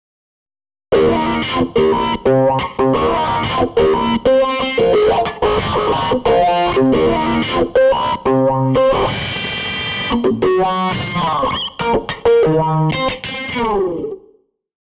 「Electric Guitar」
「２．ちょっと物足りないんで」 １．をSONARのエフェクトで処理 （MP３ 224KB）
MP３ を聴くと分かると思いますが、わずかに “プチ”系ノイズが混入してます。